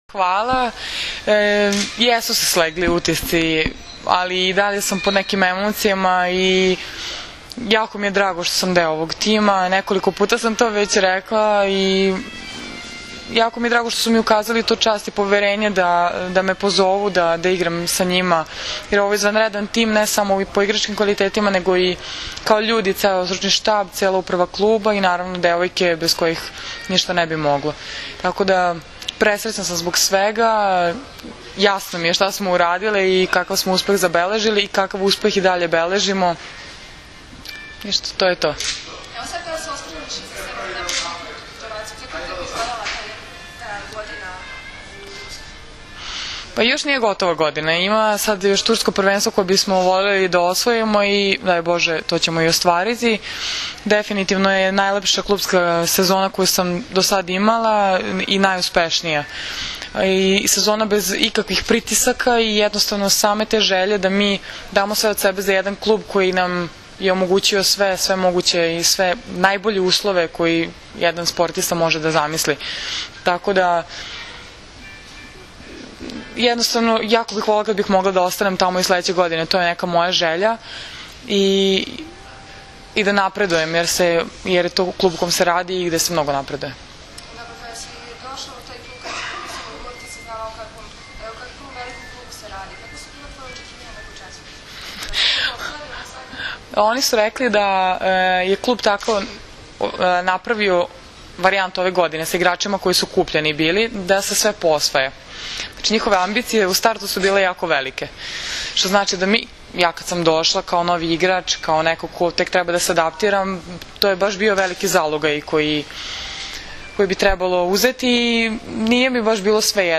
INTERVJU SA JOVANOM BRAKOČEVIĆ